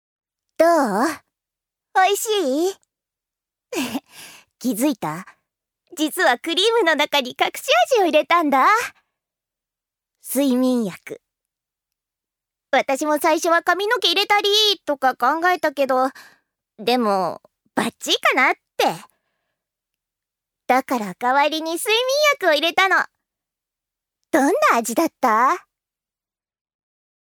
女性タレント
音声サンプル
セリフ１